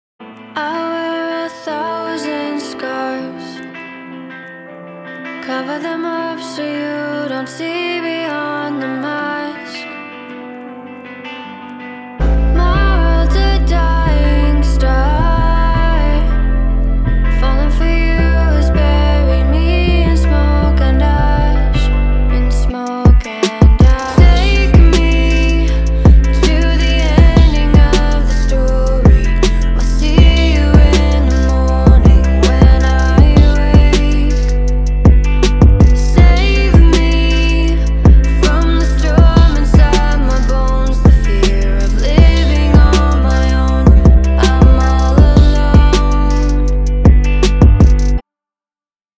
包含5条完整的无伴奏曲音轨，由两位专业歌手录制和编辑，一男一女。
除了这些高质量的无伴奏合唱之外，我们还包括75个旋律ad-lib和20个很棒的人声回路。